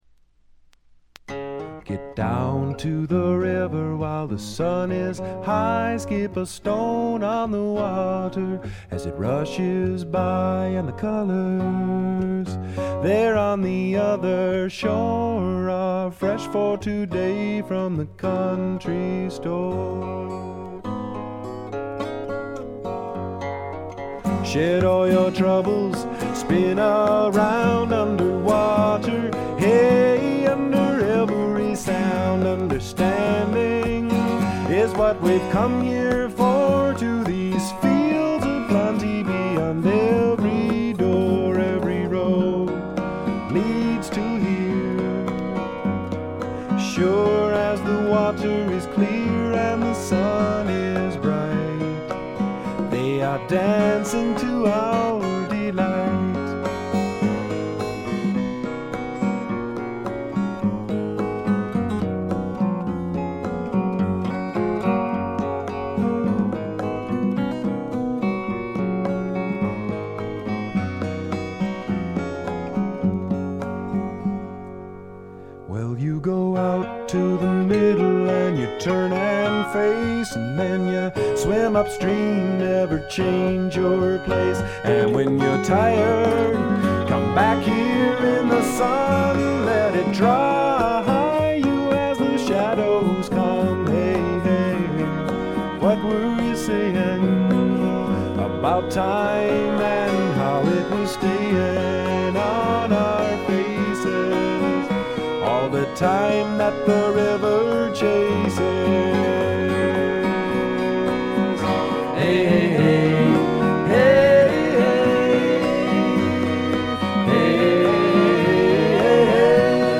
ほとんどノイズ感無し。
全体に静謐で、ジャケットのようにほの暗いモノクロームな世界。
試聴曲は現品からの取り込み音源です。
Vocals, Guitars, Harmonica